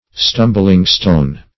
Search Result for " stumbling-stone" : The Collaborative International Dictionary of English v.0.48: Stumbling-stone \Stum"bling-stone`\, n. A stumbling-block.